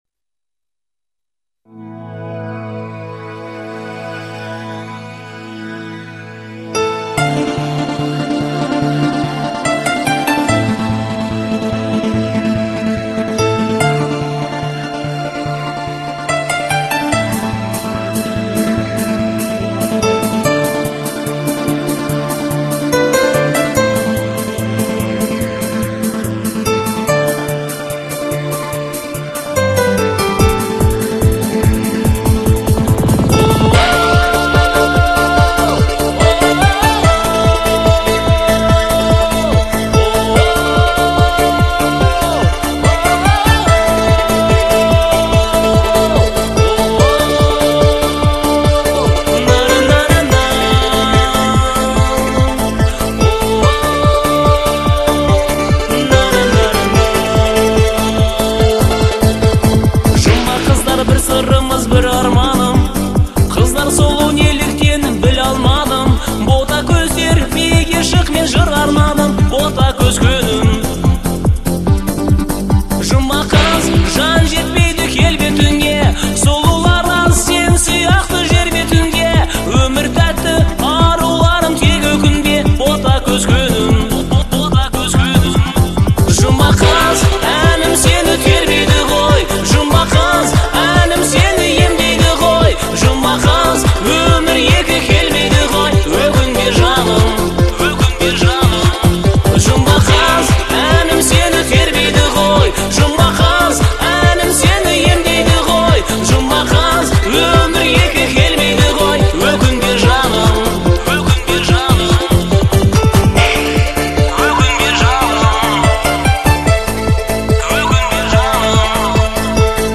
это яркая и мелодичная песня в жанре казахского фольклора